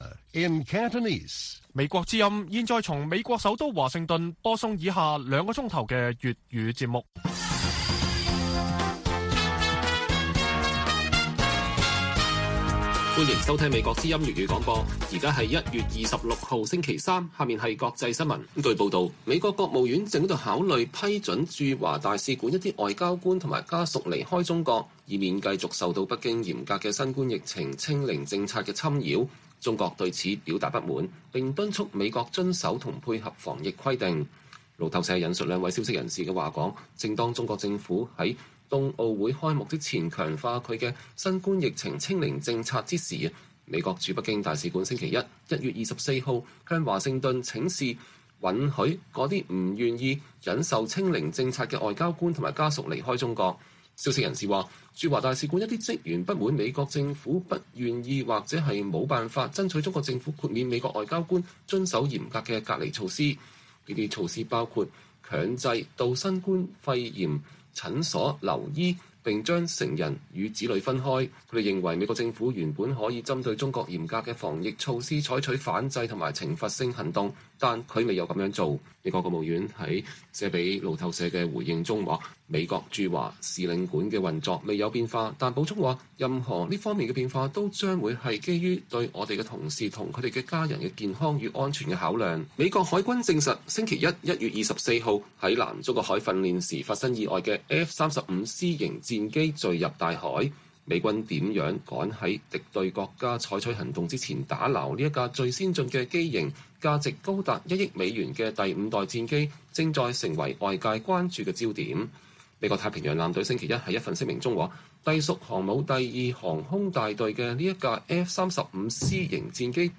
粵語新聞 晚上9-10點: 不願忍受清零防疫措施侵擾 美國駐華部份外交官據信希望離境 北京“嚴重關注和不滿”